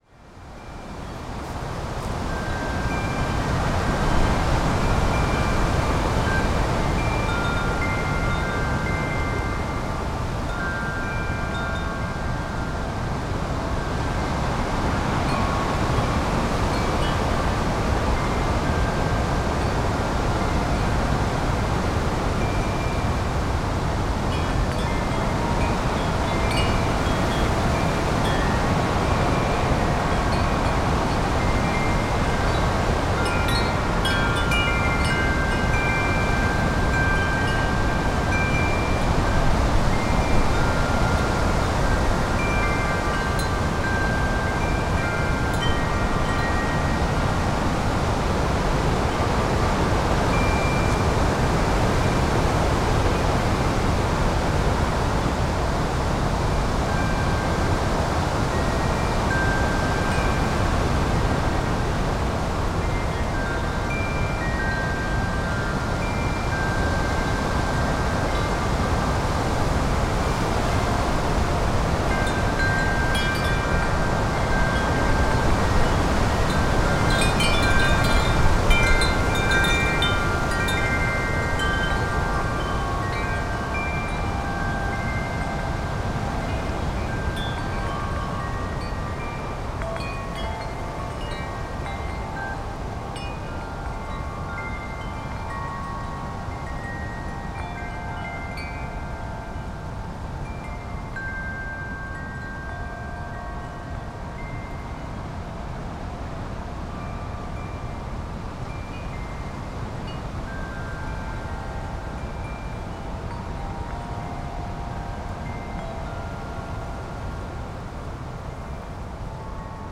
Wind Chimes in GALE over wooded valley - Pluto - Gypsy Mezzo - excerpt
Category 🌿 Nature
chimes Devon Drewsteignton England field-recording gale gusts Gypsy sound effect free sound royalty free Nature